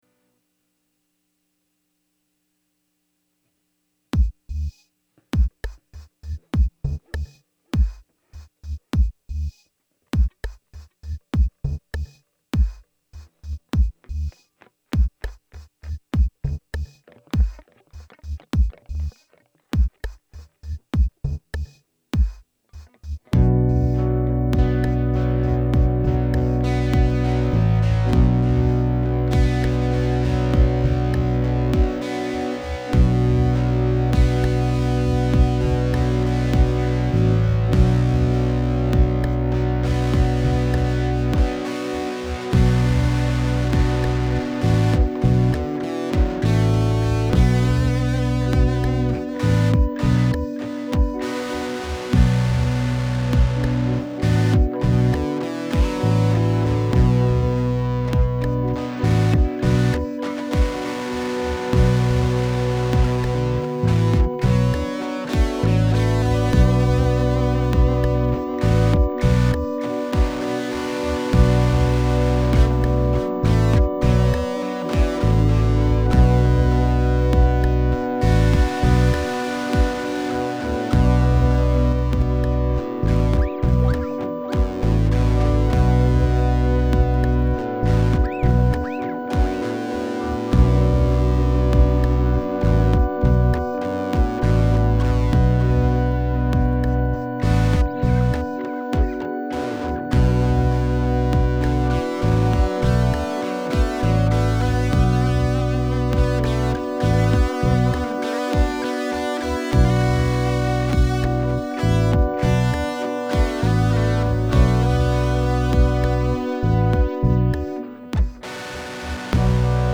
electric guitars
Camelot-Kings-03-50bpm.mp3